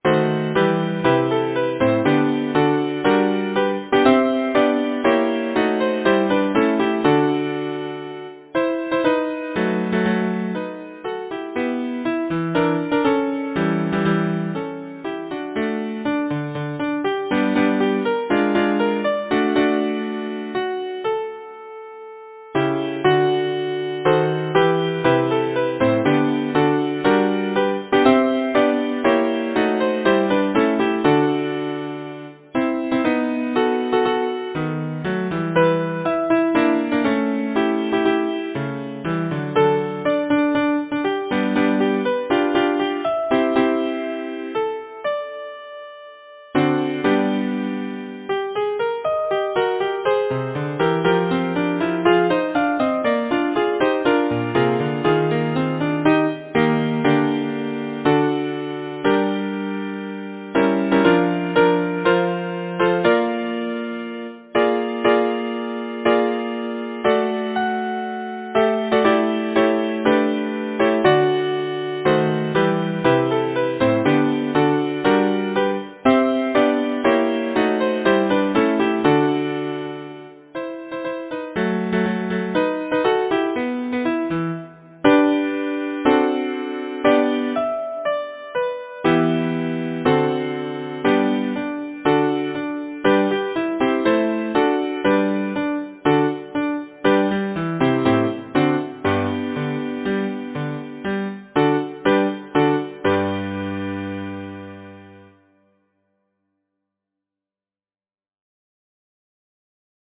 Number of voices: 4vv Voicing: SATB Genre: Secular, Partsong, Glee
Language: English Instruments: A cappella